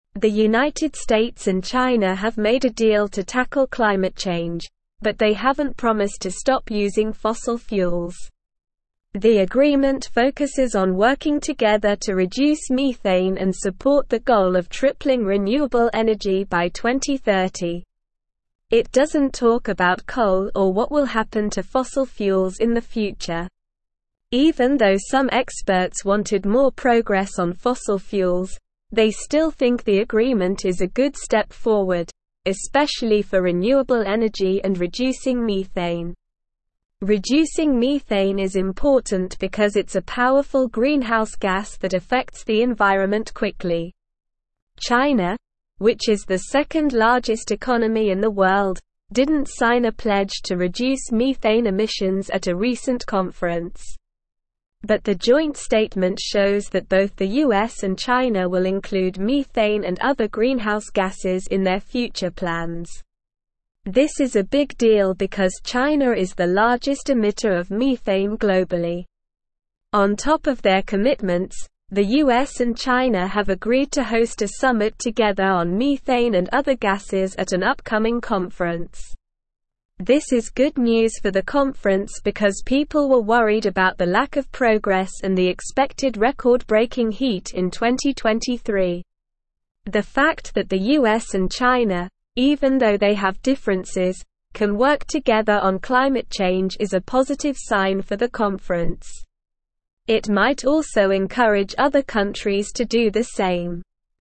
Slow
English-Newsroom-Upper-Intermediate-SLOW-Reading-US-and-China-Reach-Climate-Agreement-Address-Methane.mp3